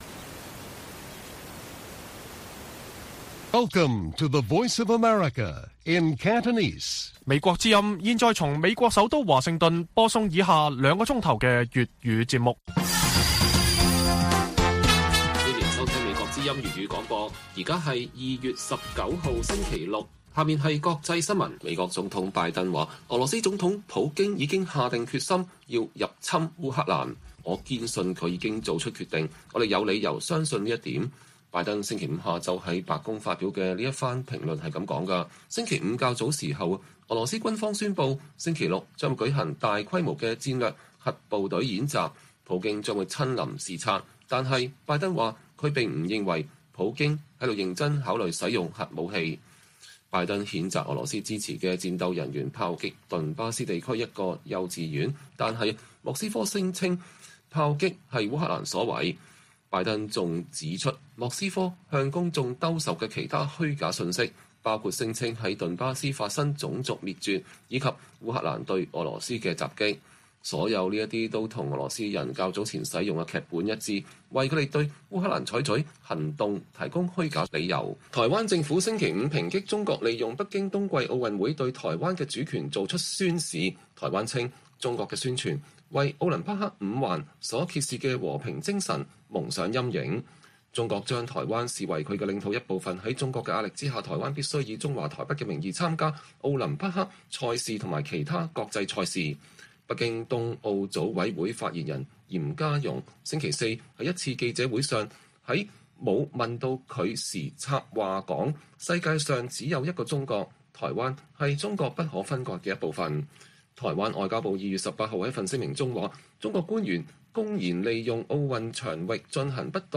粵語新聞 晚上9-10點 : 破冰50年後：美中關係更加難以管理與維繫